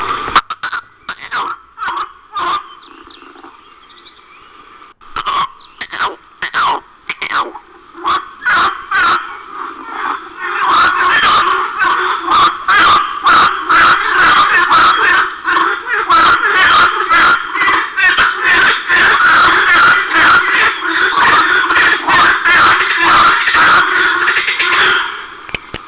...ed i risultati sono soddisfacenti...visto che...è partito un vero concerto!
ora provo a postare il canto che ho registrato pochi minuti fa al ruscello
nei secondi 1-2 sono io che lancio il "richiamo" e subito mi risponde una rana che, dopo 2 "gorgeggiate" (non sò come definirle!) conclude con un basso "trrrrrr" (secondo 3)....
..poi di nuovo io nei secondi 5-7 e poi il concertone loro...proprio alla fine si sente una risata...